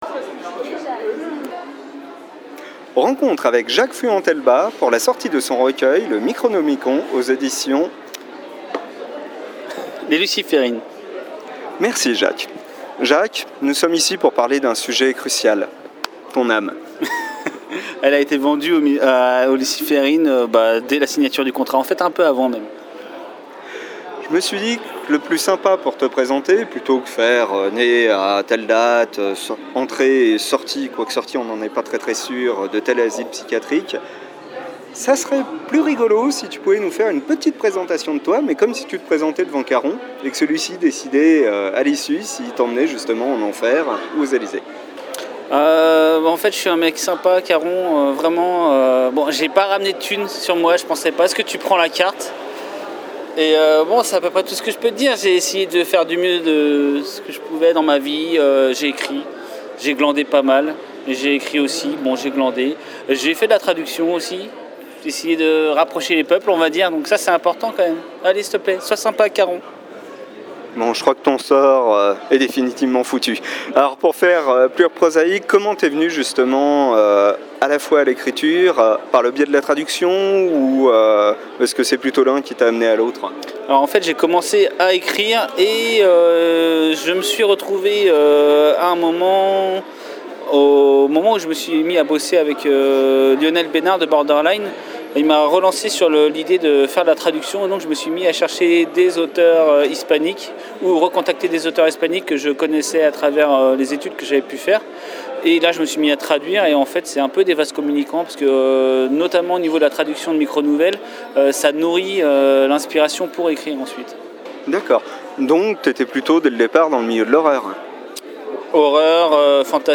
Interview 2016
Interview